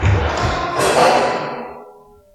hit hospital percussion sound effect free sound royalty free Memes